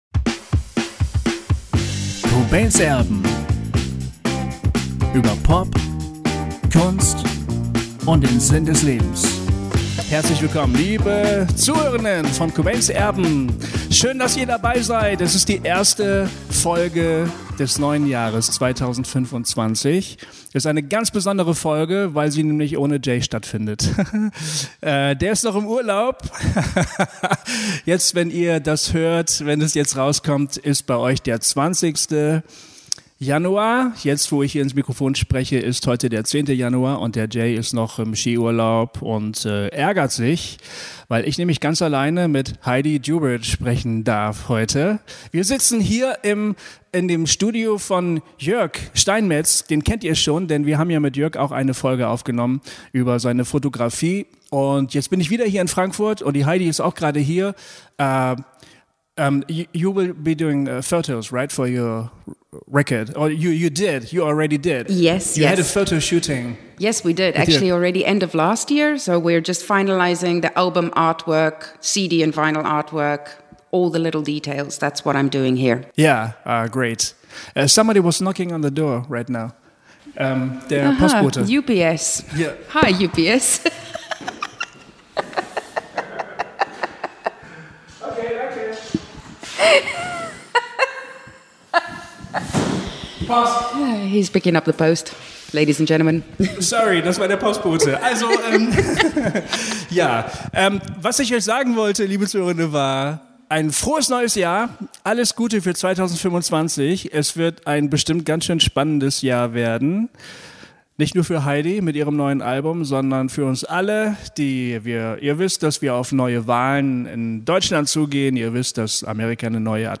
Ein ehrliches, tiefgehendes Gespräch, das wir auf Englisch geführt haben